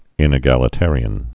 (ĭnĭ-gălĭ-târē-ən)